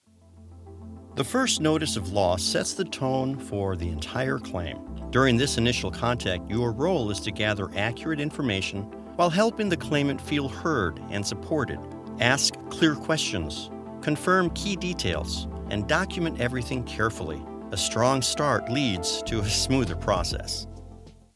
His delivery is warm, steady, and approachable, making even complex material feel manageable.
E- Learning Demos